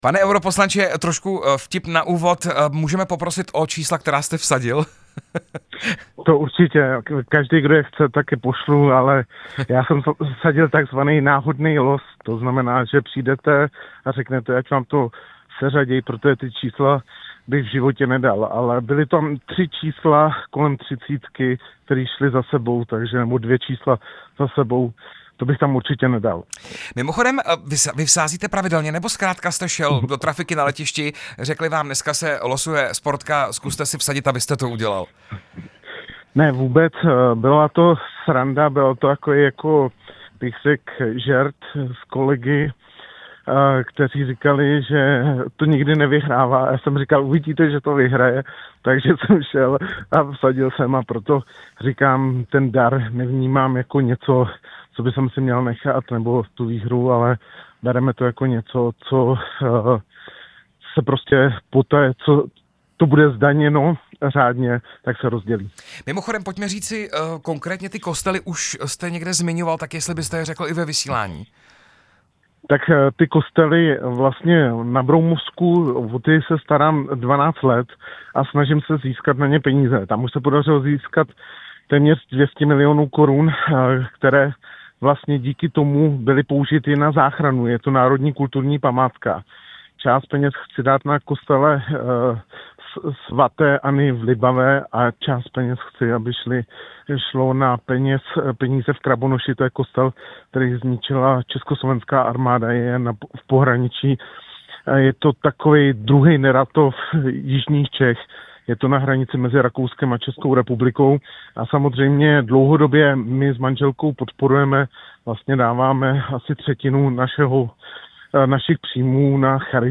Rozhovor s europoslancem Tomášem Zdechovským